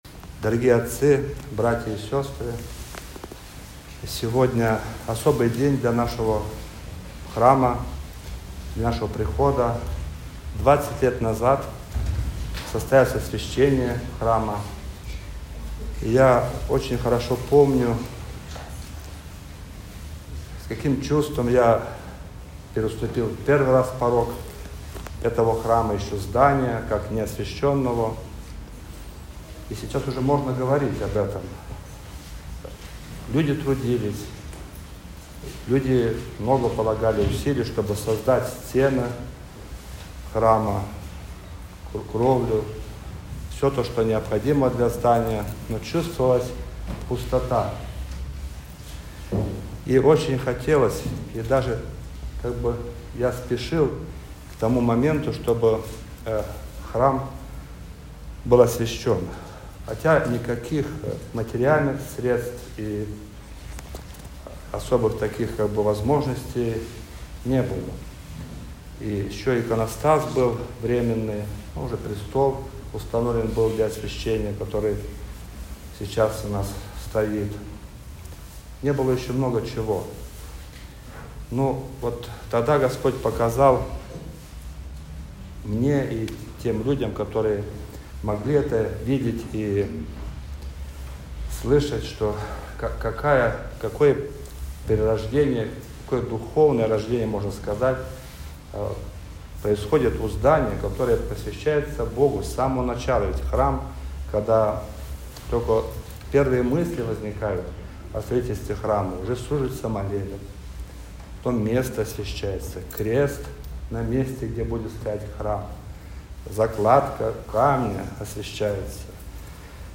Проповедь-21.12.mp3